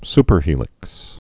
(spər-hēlĭks)